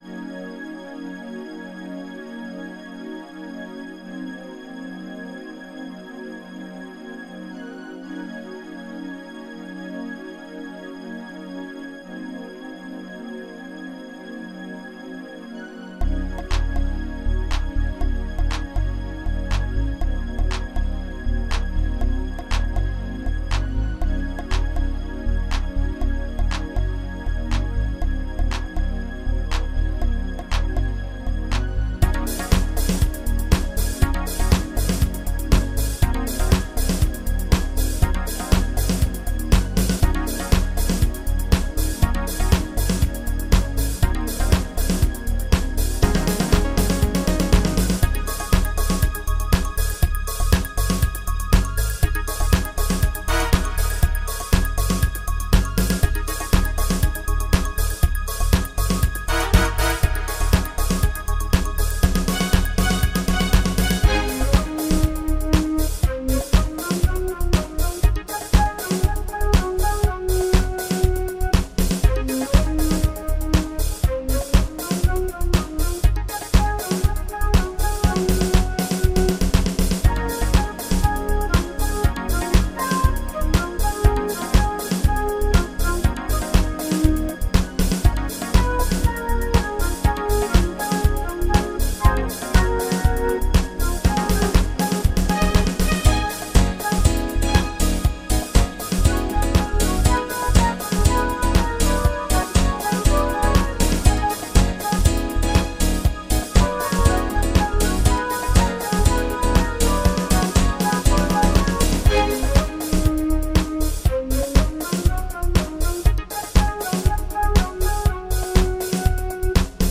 Workstation im Wohnzimmer